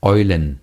Ääntäminen
Ääntäminen Tuntematon aksentti: IPA: /ˈʔɔʏ̯lən/ Haettu sana löytyi näillä lähdekielillä: saksa Käännöksiä ei löytynyt valitulle kohdekielelle. Eulen on sanan Eule monikko.